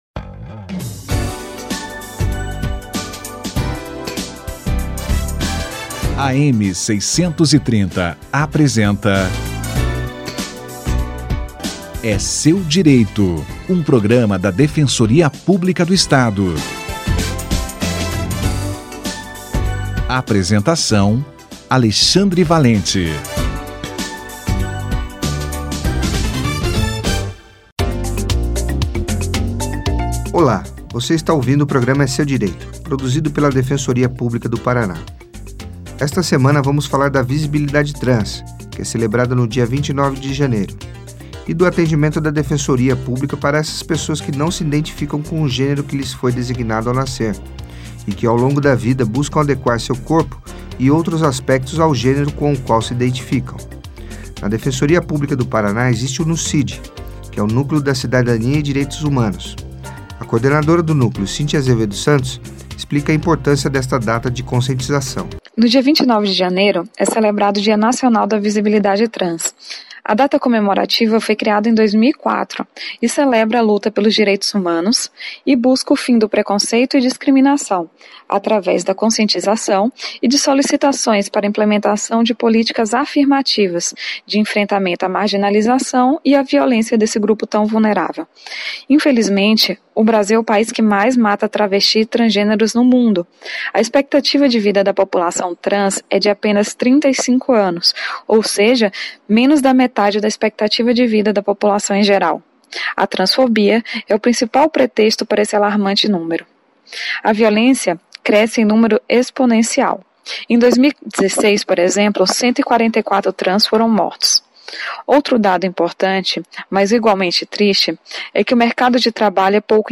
NUCIDH na visibilidade trans - entrevista